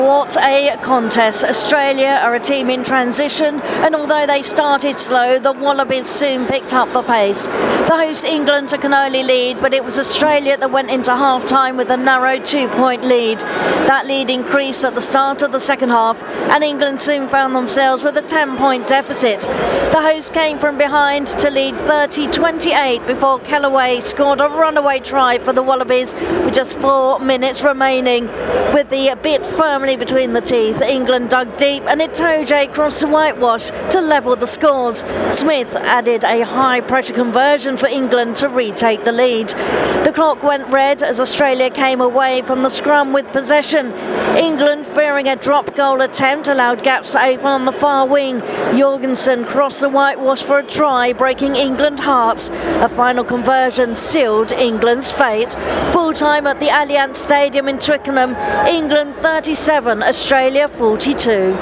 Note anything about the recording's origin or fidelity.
reports from a thrilling game at the Allianz Stadium in Twickenham: